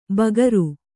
♪ bagaru